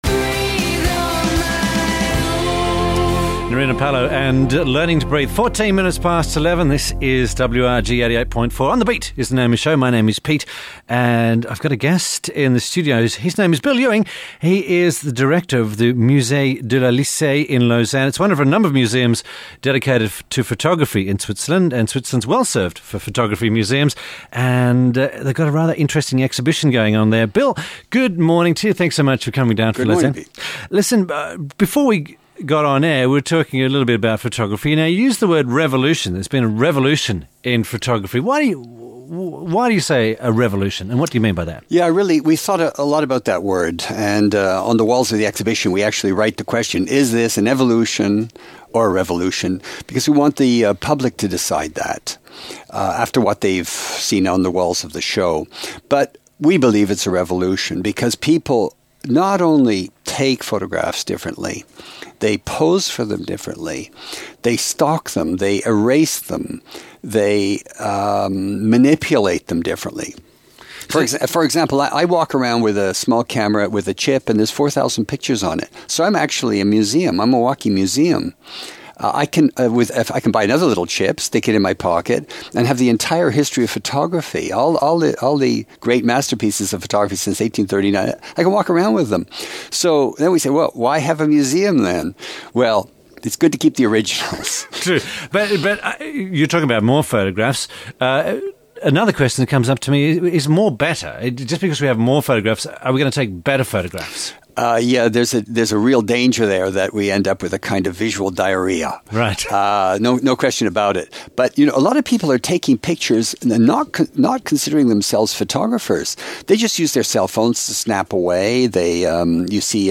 You can listen to a radio interview here .